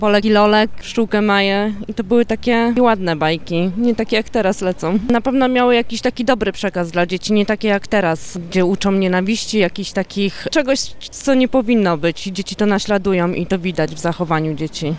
Z tą opinią zgadzają się także niektórzy stargardzianie.
mieszkanka o agresywnym przekazie bajek.mp3